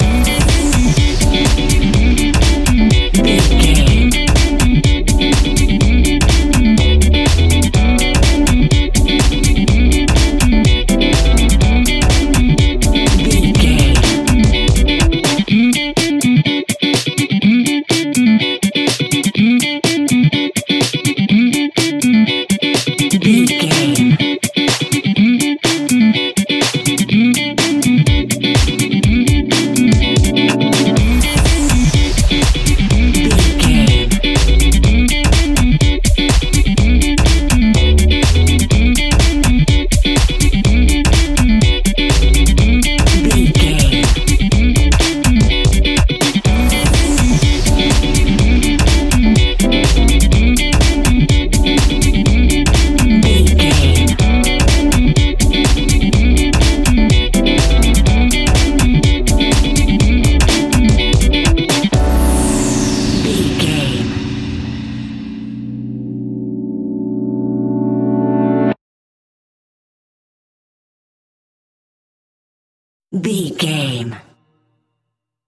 Aeolian/Minor
groovy
uplifting
energetic
bass guitar
synthesiser
electric guitar
drums
piano
nu disco
upbeat
clavinet
horns